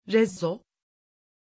Pronunciation of Rezzo
pronunciation-rezzo-tr.mp3